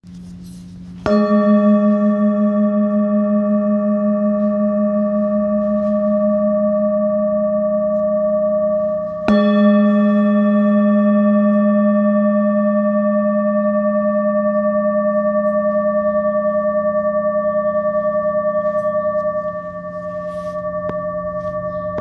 Singing Bowl, Buddhist Hand Beaten, with Fine Etching Carving, Samadhi, Select Accessories
Material Seven Bronze Metal
When played, the bowls produce a rich, harmonious sound that is said to stimulate the chakras and bring balance to the body's energy centers.